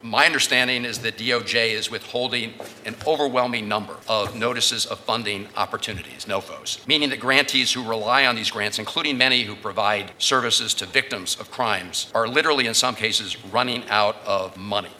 A senate appropriations committee heard testimony from US Attorney General Pam Bondi on Wednesday about Department of Justice budgets for the coming year.  Senator Van Hollen questioned Bondi about departments who are in need of funding to get through the current year…